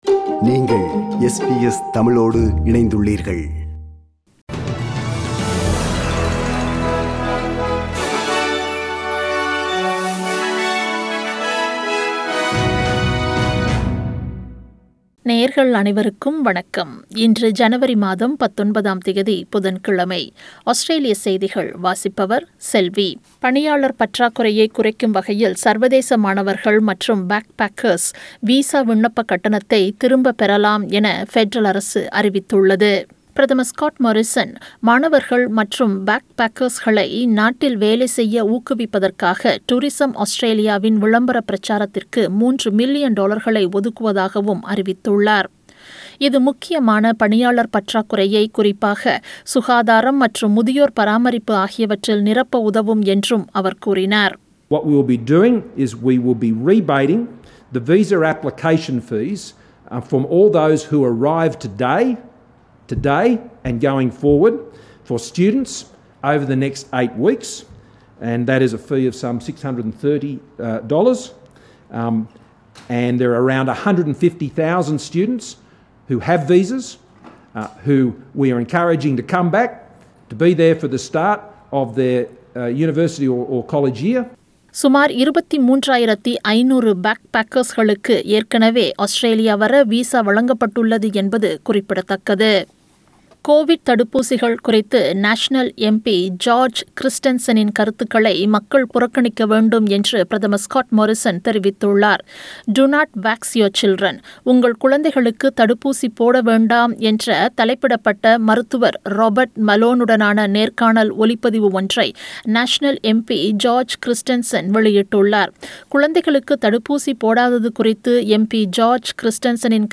Australian news bulletin for Wednesday 19 January 2022.